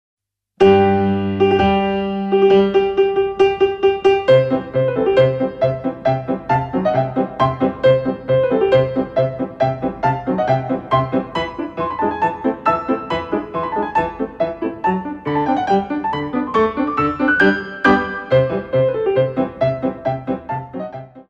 Coda pour manége & fouéttés